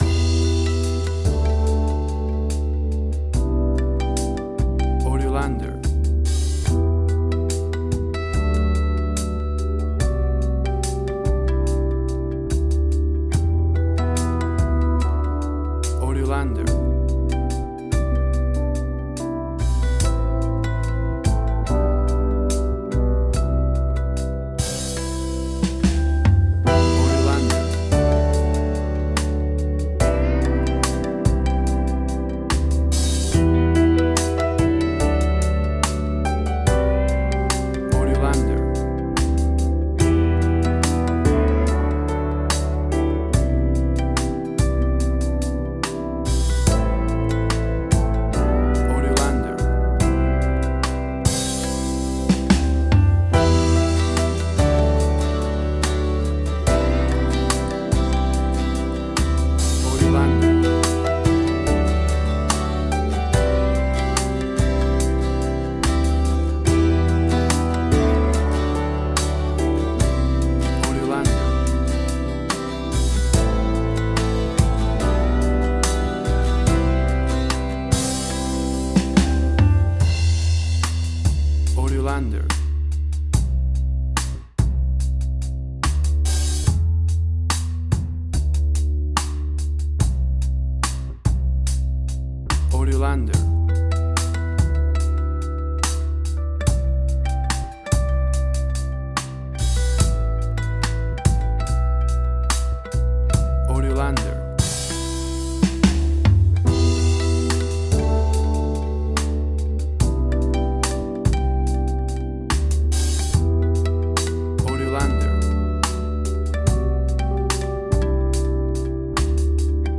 Tempo (BPM): 72